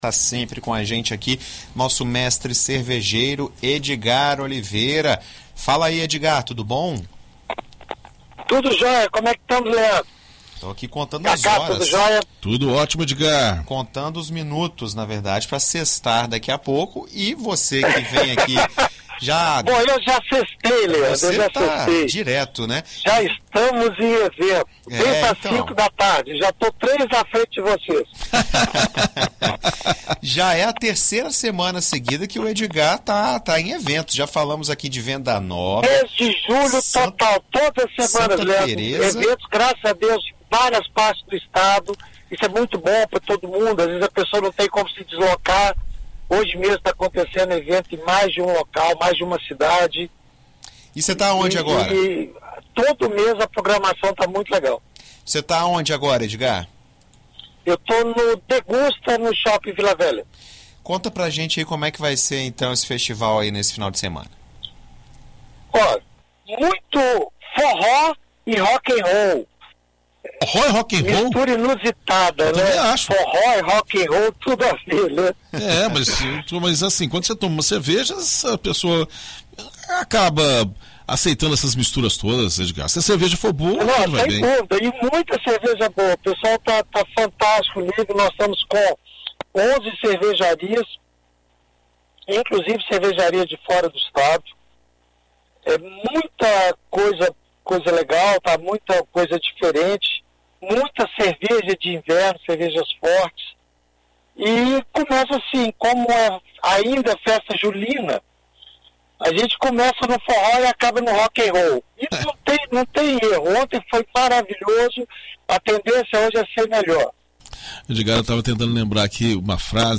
Em entrevista à BandNews FM Espírito Santo nesta sexta-feira